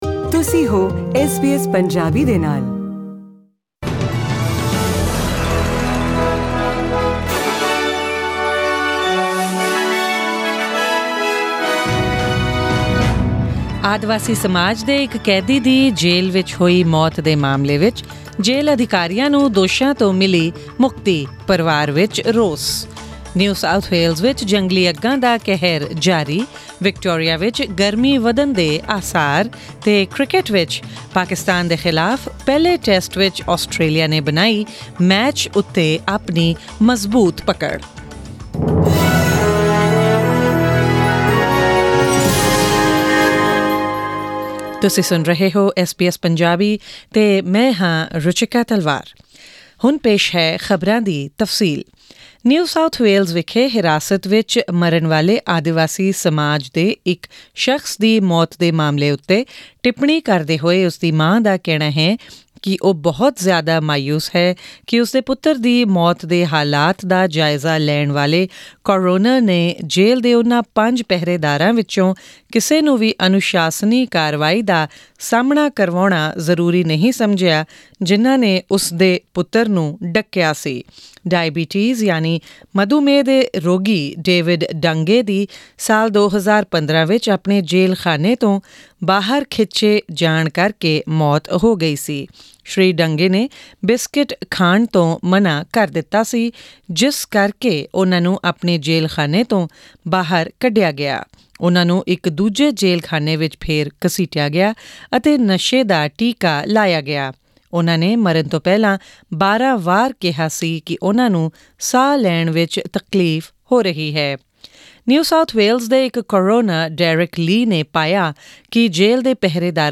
Australia get firm grip over Pakistan in the Brisbane Test Click on the player at the top of the page to listen to the news bulletin in Punjabi.